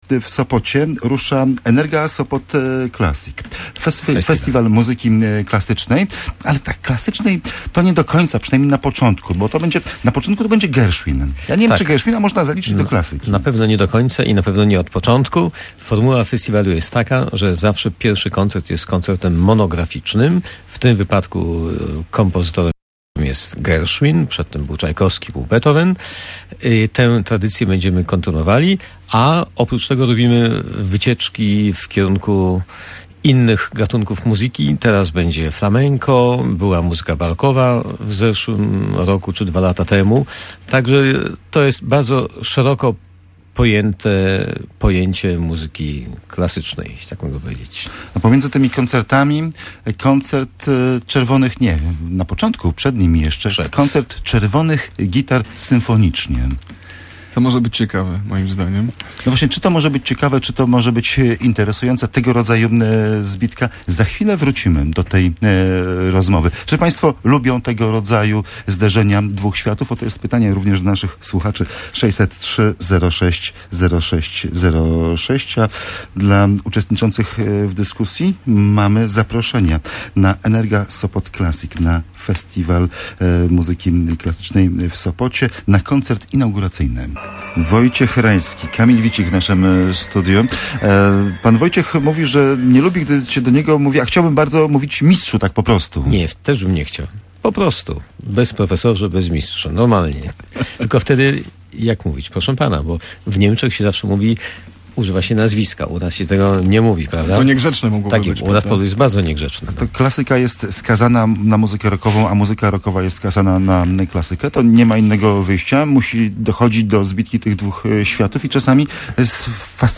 Tym razem Przepis na Sopot gościł na antenie Radia Gdańsk w nieco rozbudowanym wymiarze.